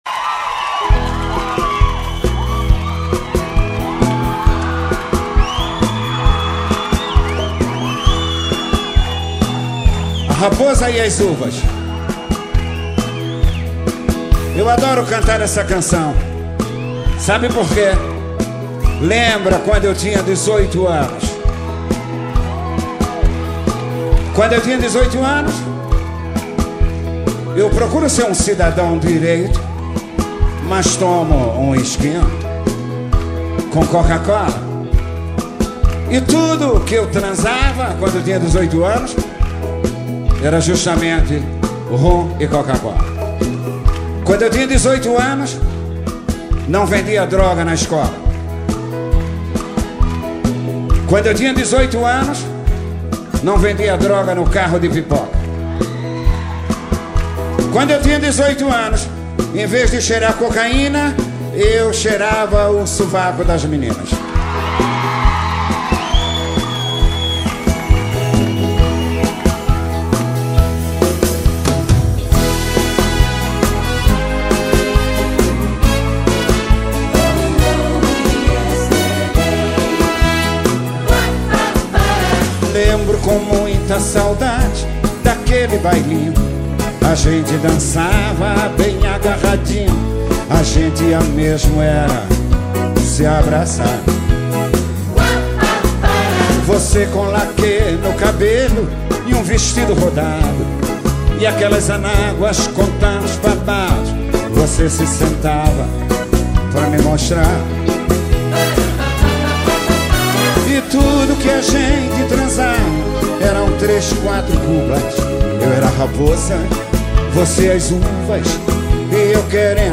2024-06-07 22:42:17 Gênero: MPB Views